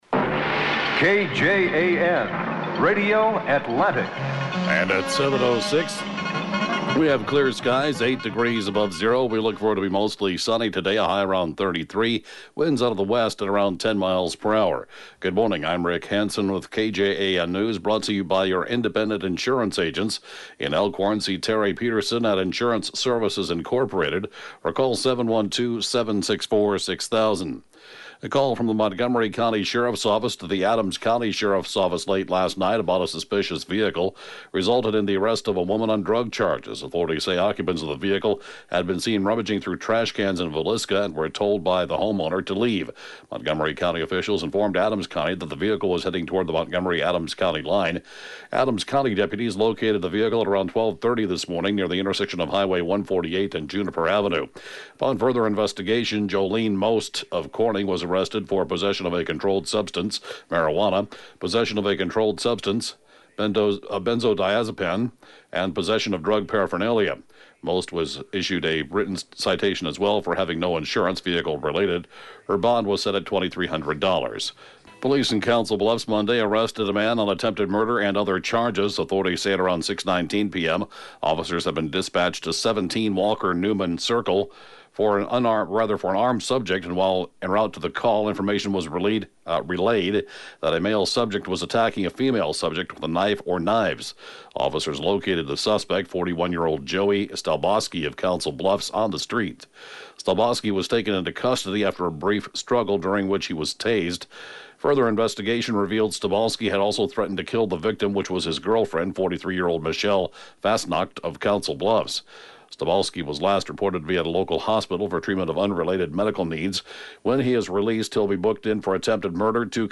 (Podcast) KJAN Morning News & Funeral report, 11/13/2018